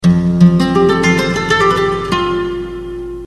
guitar nș 140
guitar140.mp3